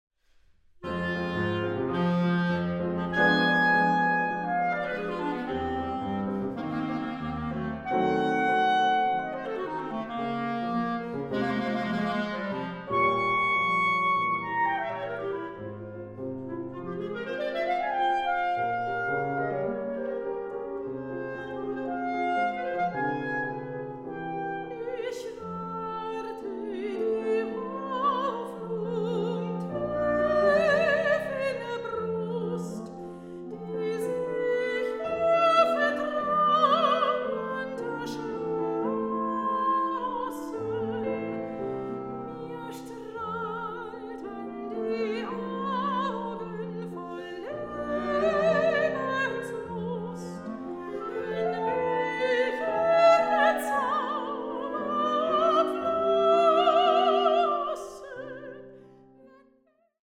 Klarinette
Klavier
Sopran
Aufnahme: Tonstudio Ölbergkirche, Berlin, 2023